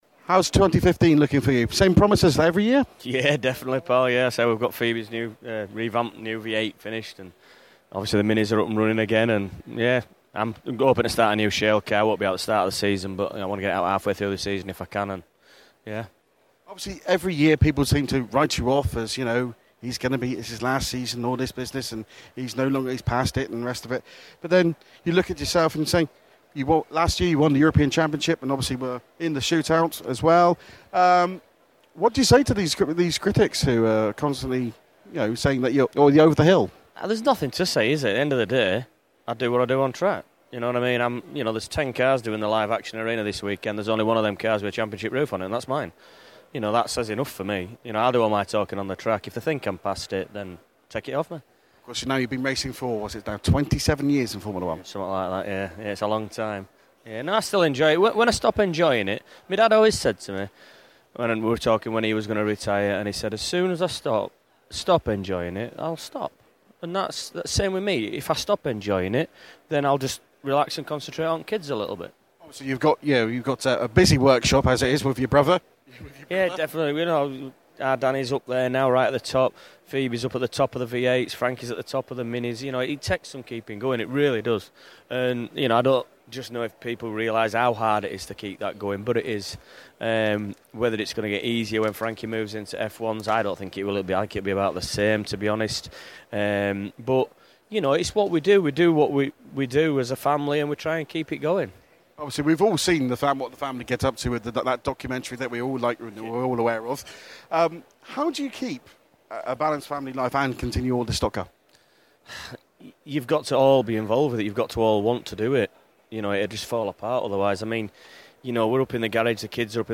Autosport Show - Interview